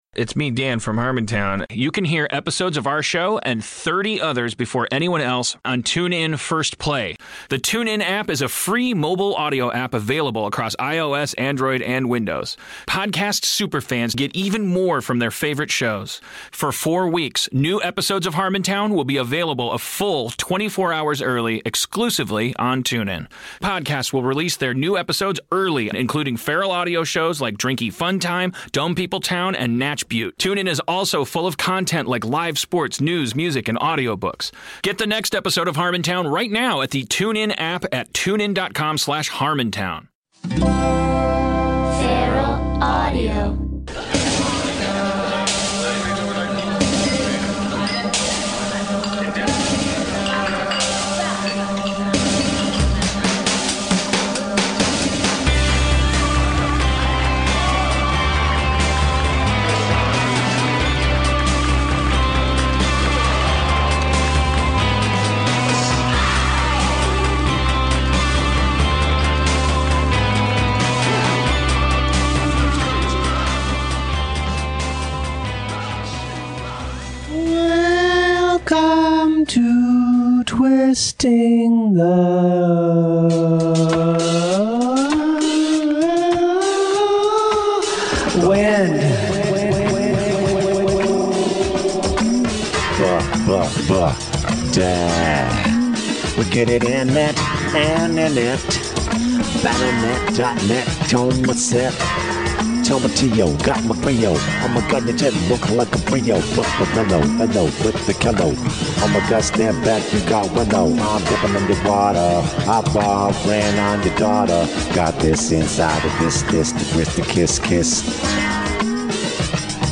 A specialist solo episode filled with goodie nuggets. Music and a call y’all. get dipped!